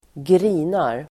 Uttal: [²gr'i:nar]